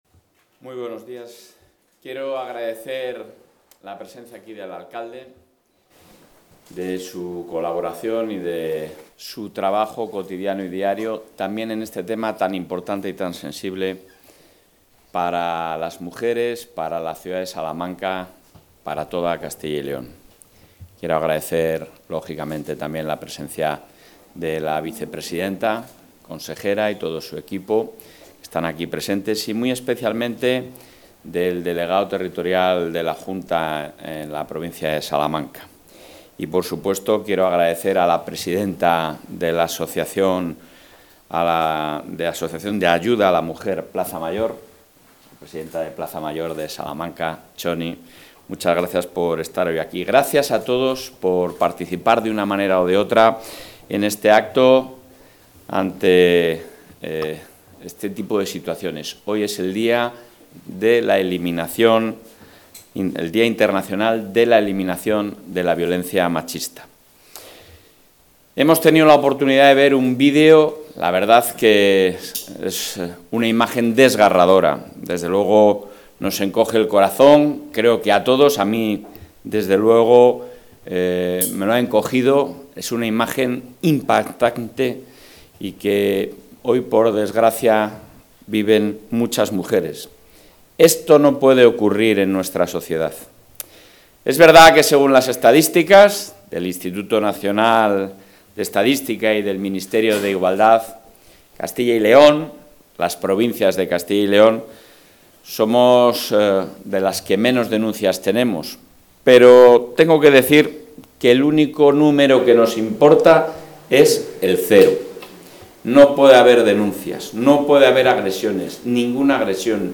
Intervención del presidente de la Junta.
El presidente de la Junta de Castilla y León, Alfonso Fernández Mañueco, ha inaugurado hoy las instalaciones del centro de atención a víctimas de agresiones sexuales 'Atiendo' en Salamanca, un modelo que se extenderá también al resto de capitales de provincias de la Comunidad, y en los que se va a brindar una asistencia inmediata y coordinada las 24 horas, todos los días del año, ante cualquier situación de delito sexual.